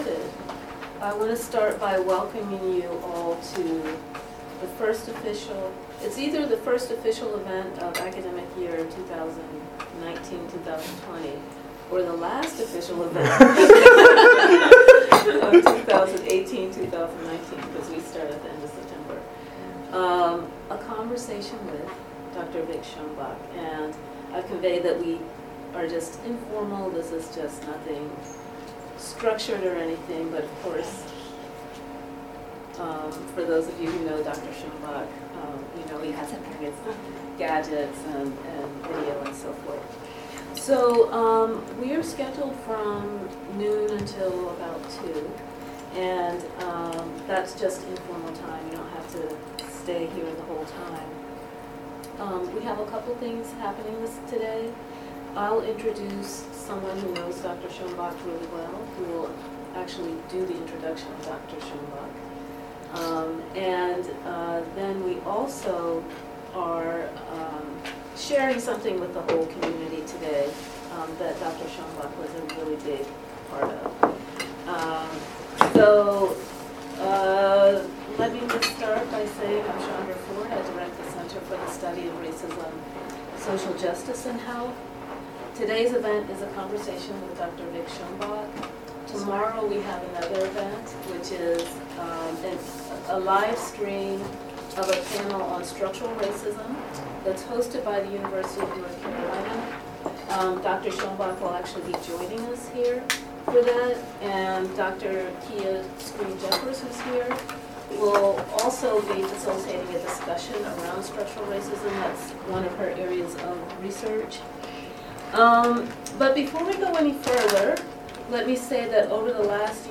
Venue: UCLA Fielding School of Public Health Center for the Study of Racism, Social Justice, and Health, September 12,2019 Presentation slides - PDF Presentation slides - Powerpoint 2-hour audio recording